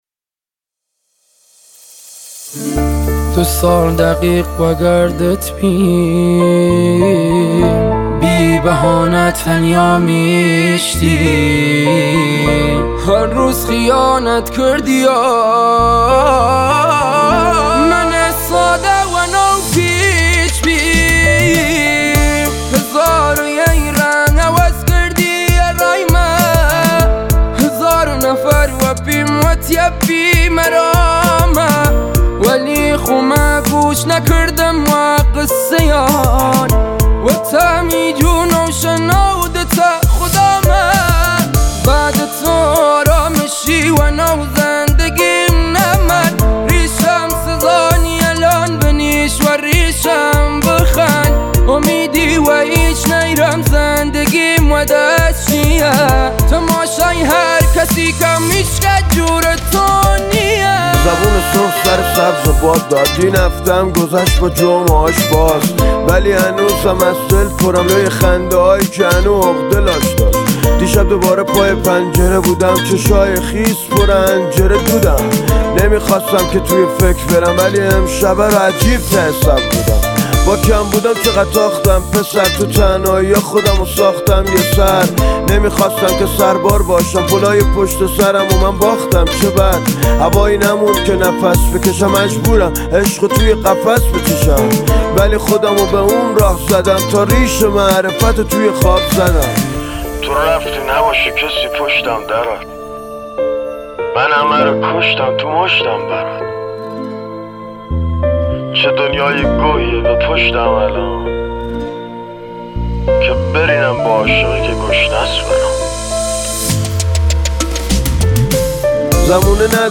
موزیک کردی